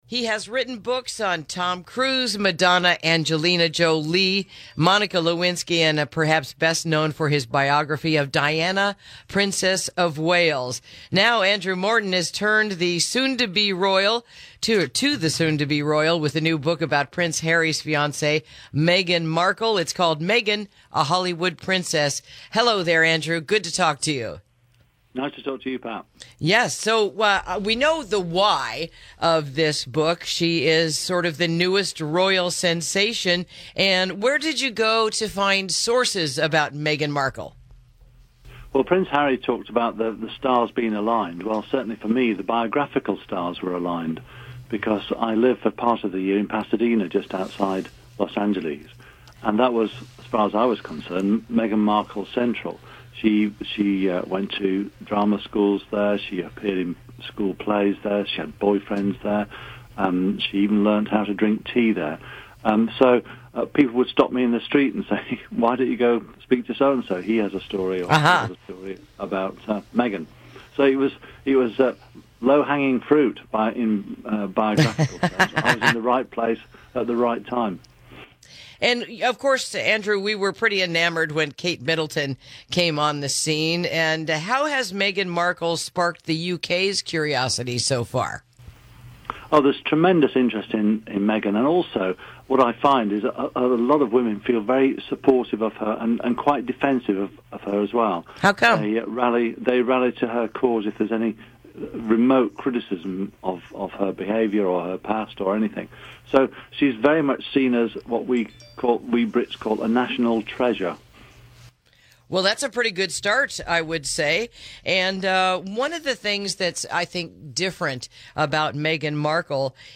Interview: The Impending Nuptials of the British Royal Couple